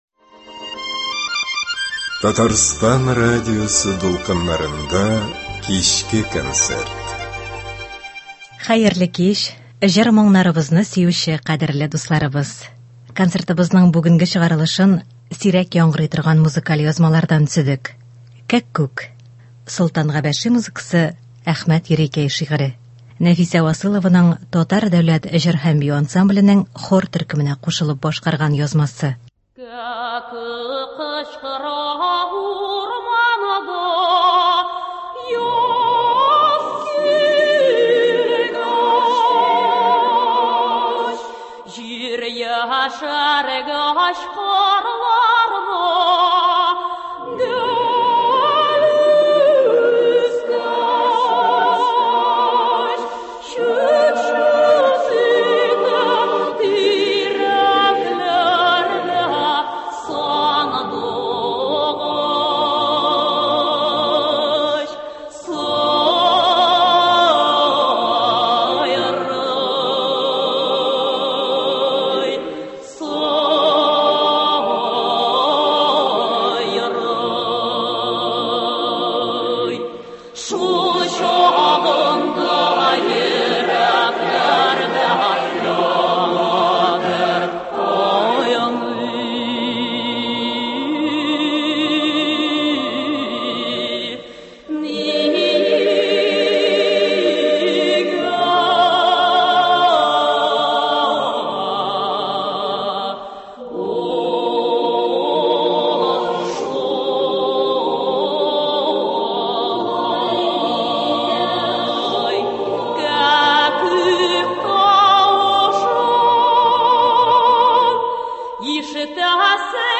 Концерт.